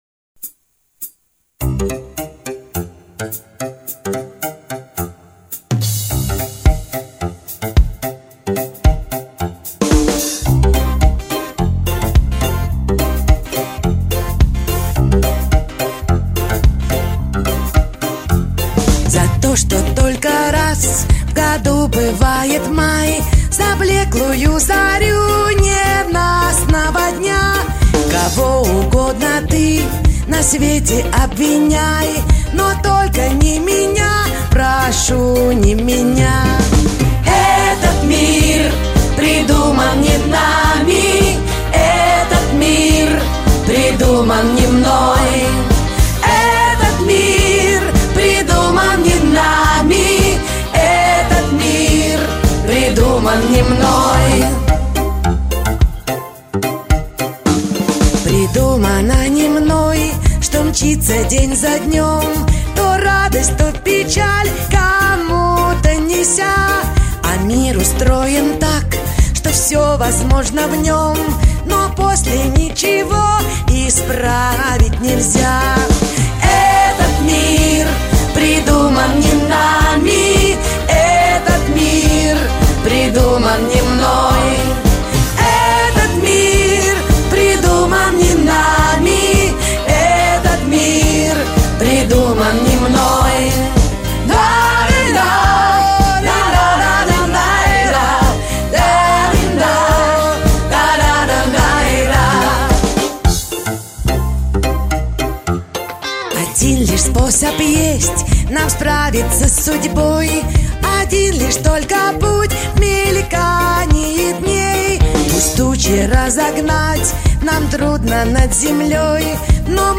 но уж очень ремиксованная.